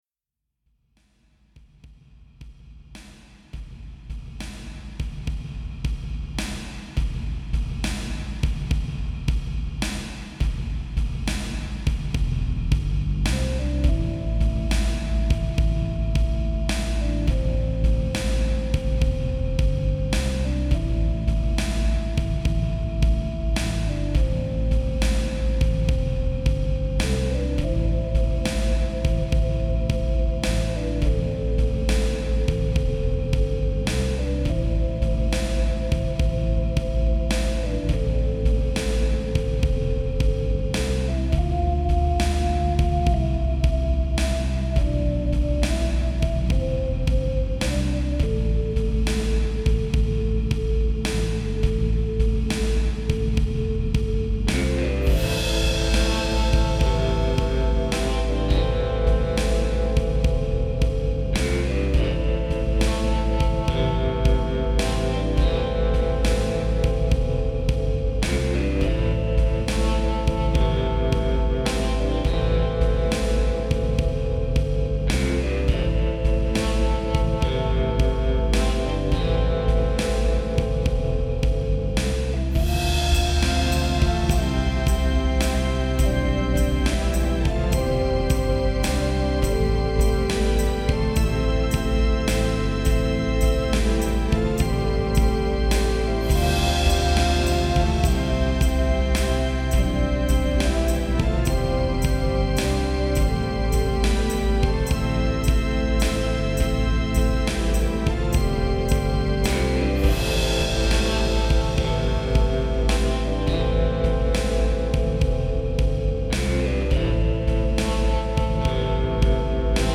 Home recording project/song.
This was all written on a Roland Di keyboard, then recorded using the latest Audacity. I can't afford decent monitors right now (from everything I've read it doesn't even seem worth picking up a pair if I can only spend $200-$300 on them) and so yes, it was all mixed mono through headphones, on Audacity.
When I finished all of the tracks there was a bit of clipping and so I selected all tracks and de-amplified so as to get them out of the red. That works but comparatively the track is about half of the volume of the reference track that I'm using.